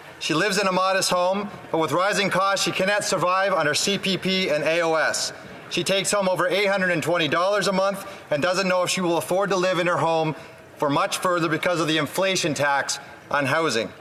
MP Ryan Williams speaks on housing and inflation in House of Commons